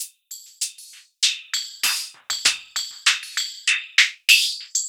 98WAGONFX3-R.wav